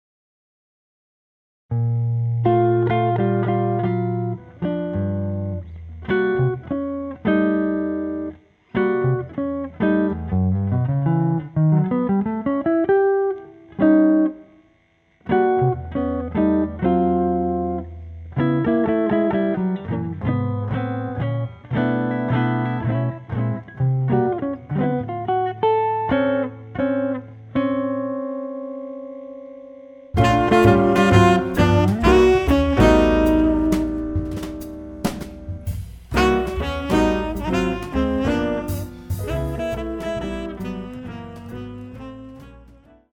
guitar
sax
piano
bass
drums